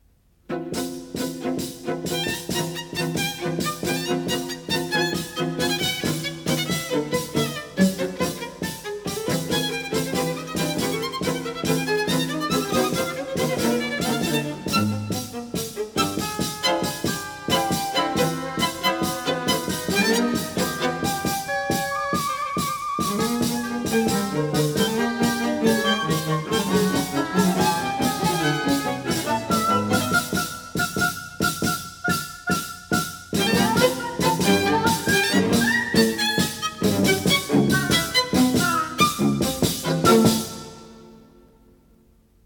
Сюита из музыки к кинофильму